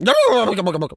pgs/Assets/Audio/Animal_Impersonations/turkey_ostrich_gobble_04.wav at 7452e70b8c5ad2f7daae623e1a952eb18c9caab4
turkey_ostrich_gobble_04.wav